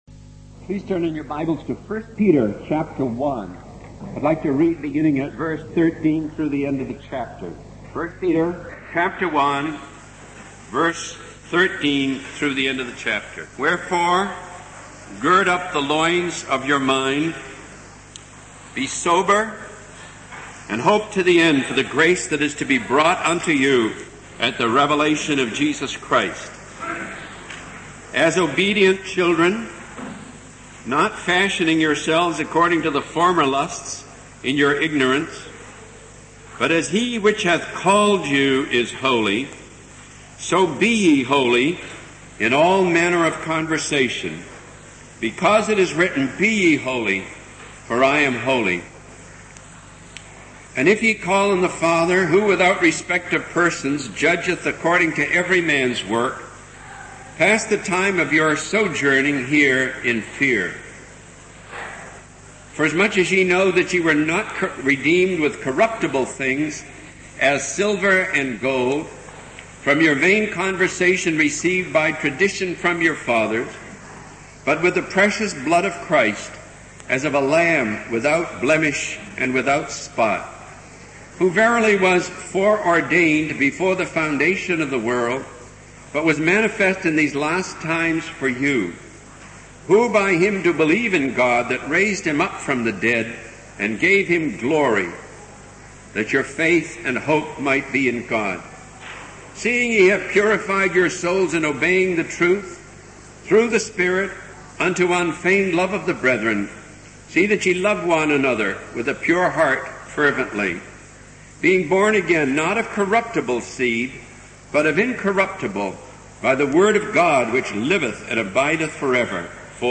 In this sermon, the speaker expresses disappointment in hearing about Christians who compromise their faith and engage in shady associations.